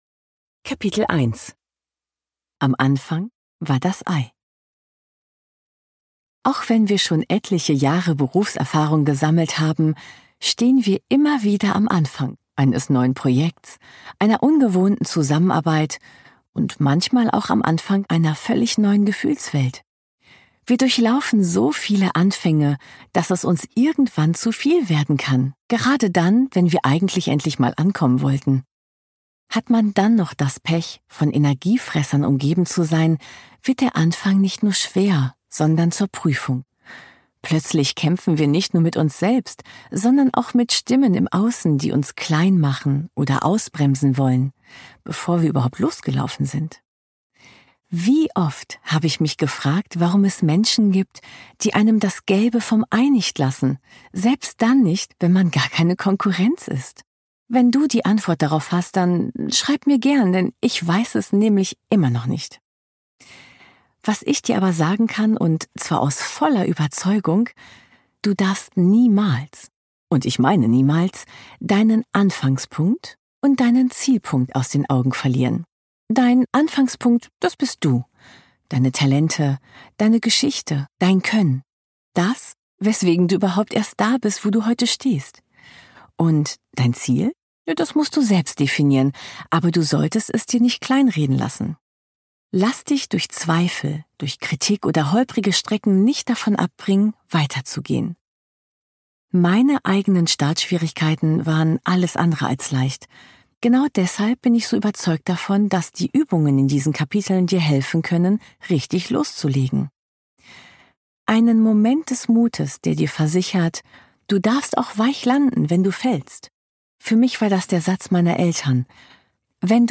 Zum Hörbuch: Das Hörbuch ist mehr als eine gelesene Version des Buches. Es ist ein persönliches Gespräch.